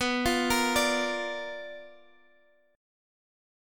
Bb/B chord